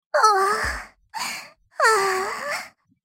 Sound Effect: Anime female moans - The AI Voice Generator
Listen to the AI generated sound effect for the prompt: "Anime female moans".